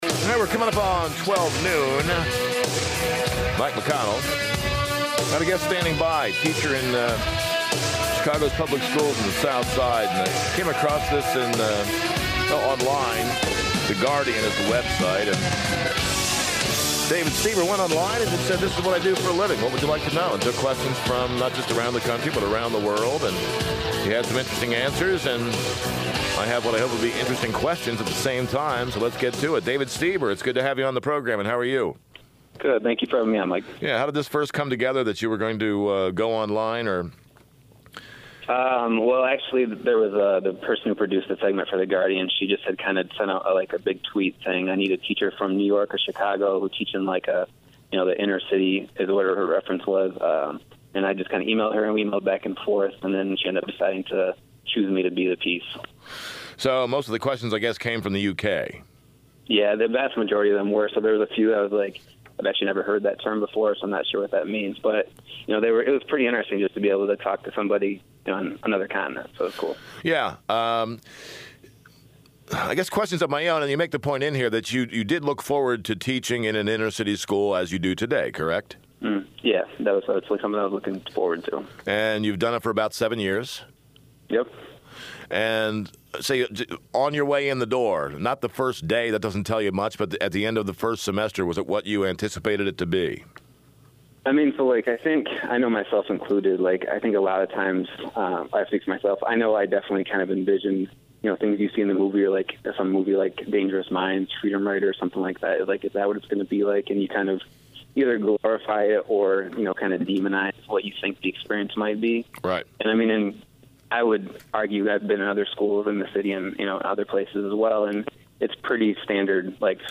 Other than him trying to get me to talk bad about my student’s parents, calling my kids gang bangers, and implying that because I worked in Englewood I must not be a good teacher, because I couldn’t get a job somewhere else, the interview went pretty well.